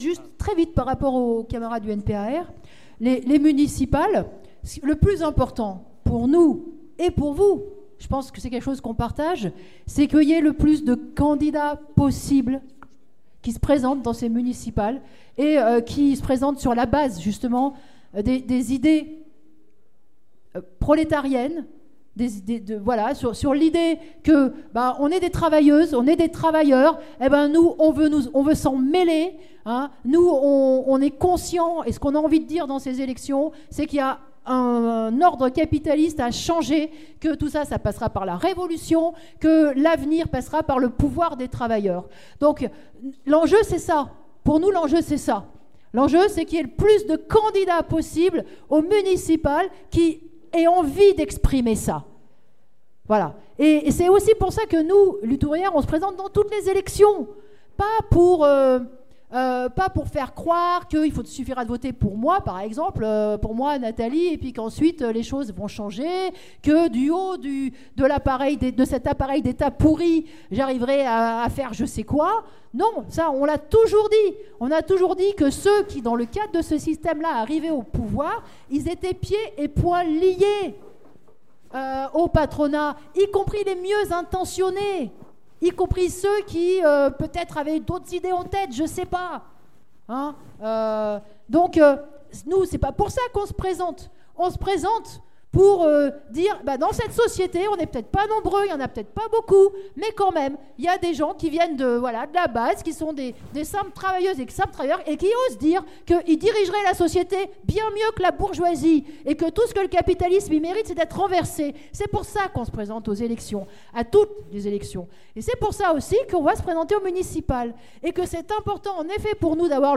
Nathalie Arthaud débat à la fête lyonnaise de LO : Affaire Sarkozy: l'État au service de la bourgeoisie